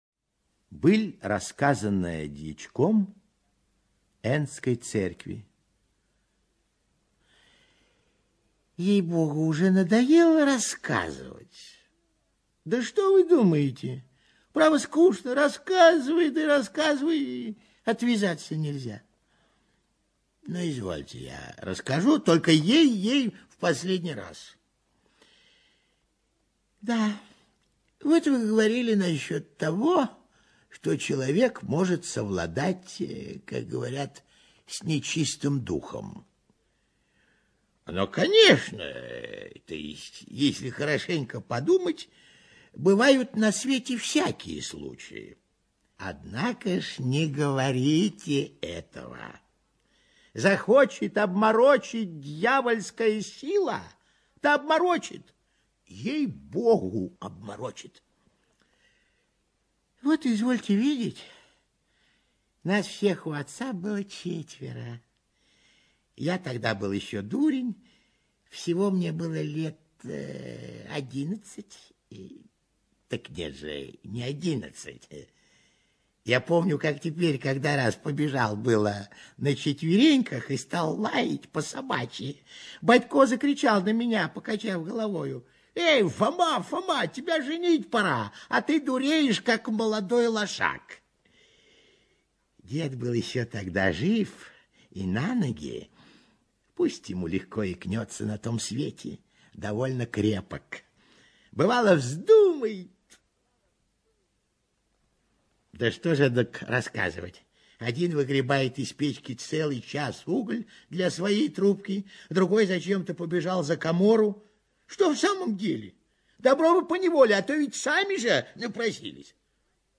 ЧитаетПапанов А.